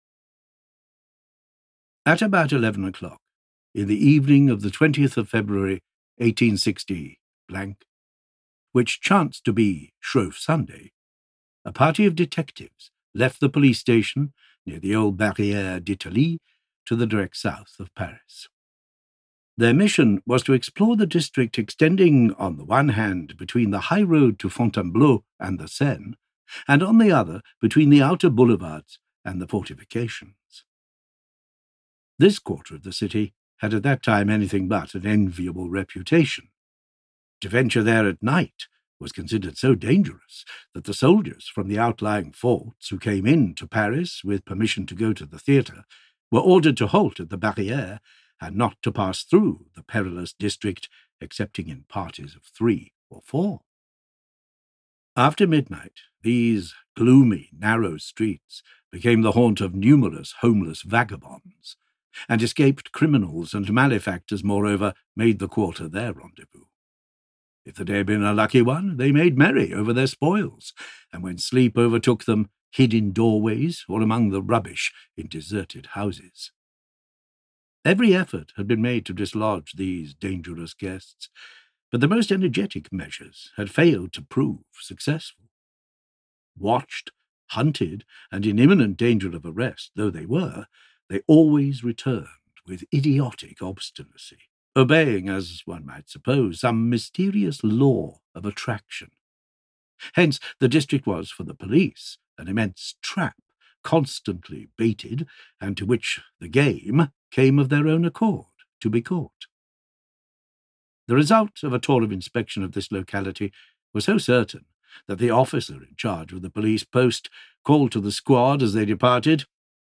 Monsieur Lecoq audiokniha
Audiobook Monsieur Lecoq, written by Émile Gaboriau.
Ukázka z knihy